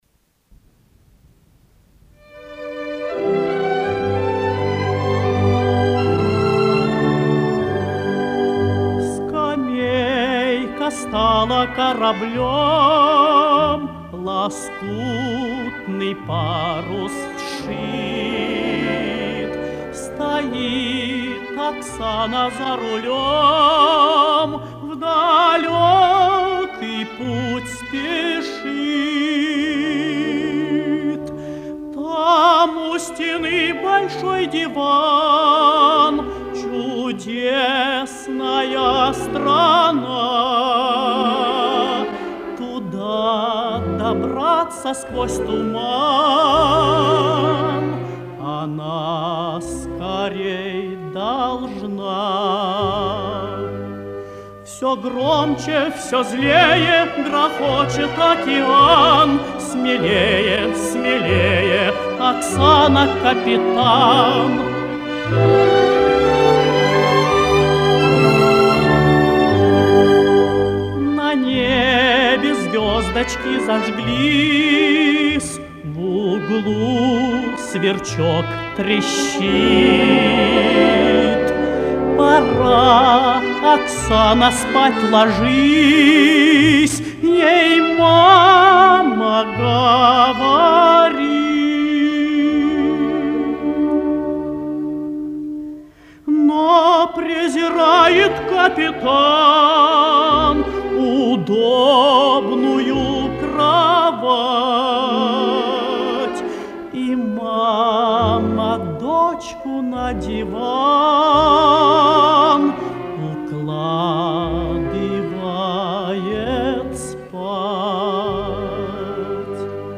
Можно сказать, что колыбельная.